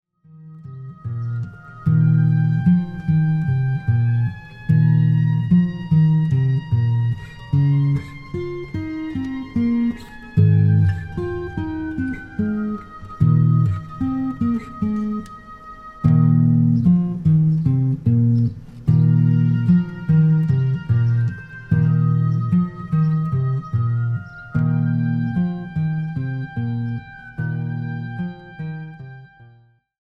Eb major